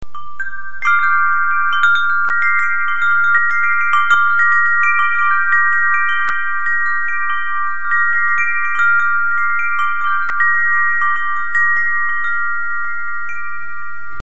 Eine Serie von Windspielen mit gleicher Tonfolge in verschiedenen Größen und entsprechenden Tonlagen.
Jeweils in pentatonischer Stimmung.
Pluto (Gesamtlänge 69 cm)
Klangbeispiel Windspiel Pluto